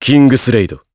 Riheet-Vox_Kingsraid_jp.wav